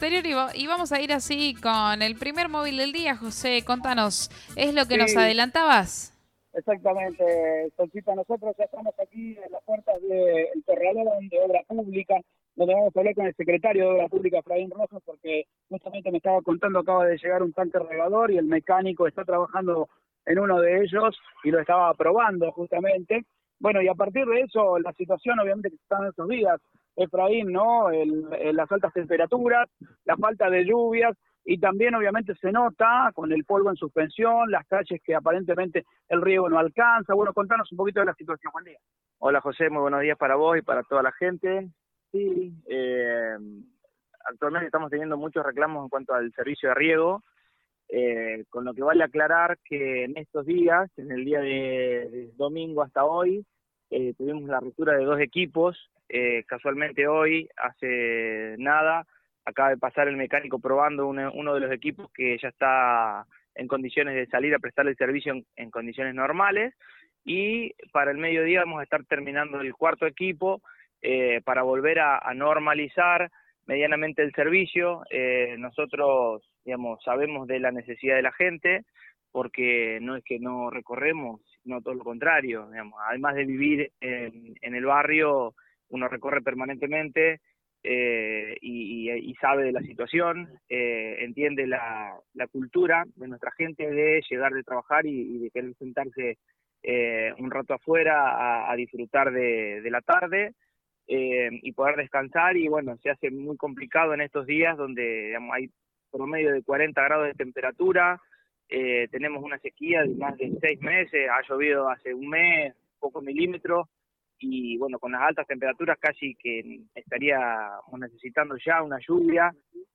Ante los reclamos de los vecinos por la falta de riego, el Secretario de Obra Pública Efraín Rojas brindó un panorama de la situación.